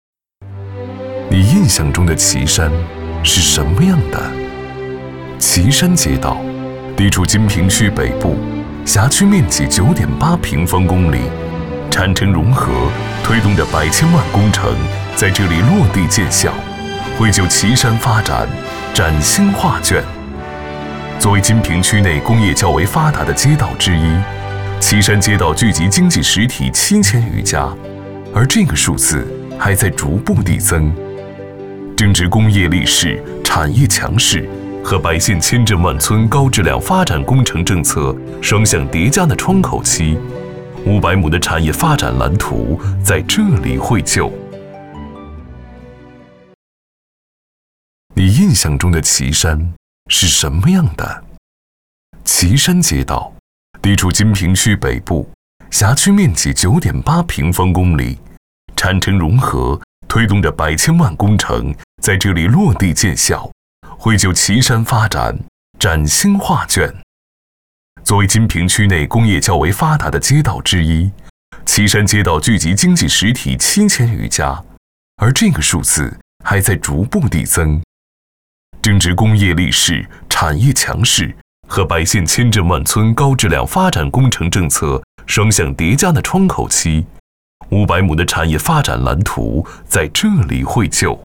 男配音-配音样音免费在线试听-第53页-深度配音网
男215-专题-叙述感称述—岐山街道
男215磁性录音 215
男215-专题-叙述感称述-岐山街道.mp3